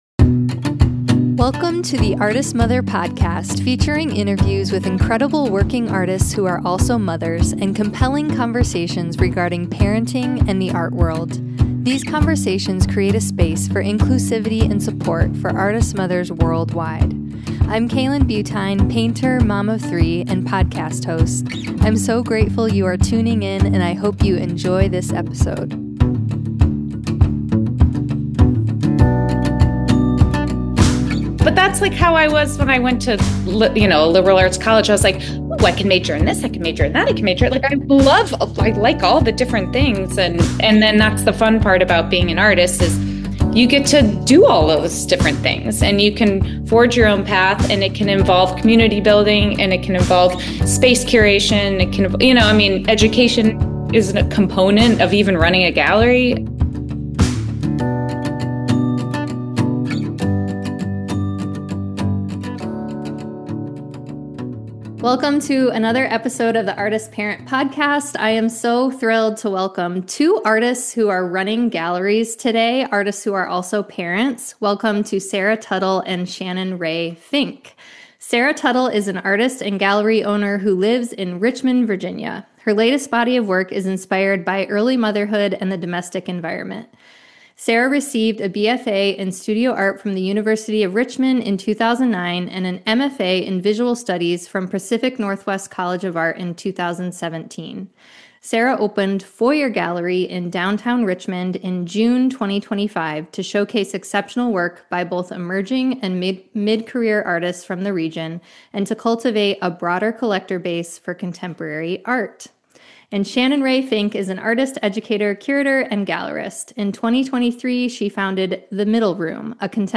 1 170: Integrating Multiplicity with two Artist Parent Gallerists 1:23:04 Play Pause 3d ago 1:23:04 Play Pause Play later Play later Lists Like Liked 1:23:04 In this candid conversation, I sit down with two artist-parents-turned-gallery-owners who open up about the balance, the burnout, and the unexpected joys of wearing multiple creative hats.